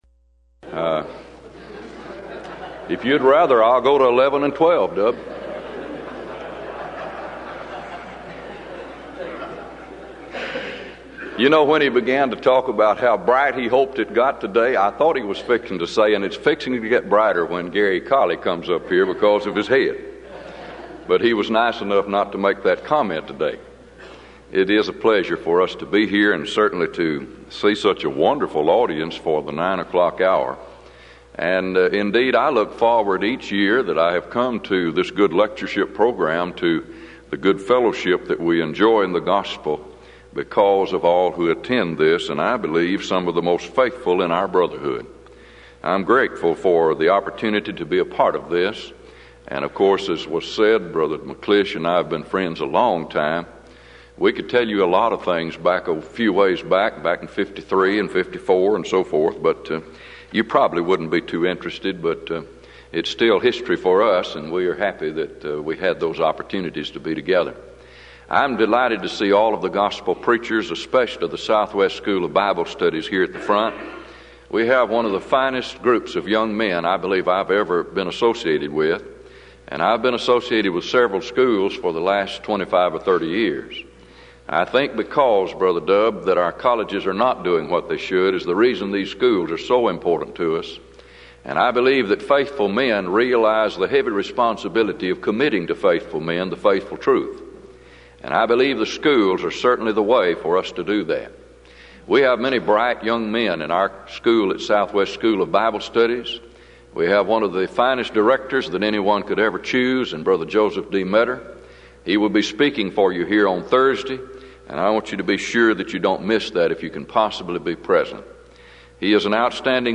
Event: 1994 Denton Lectures
lecture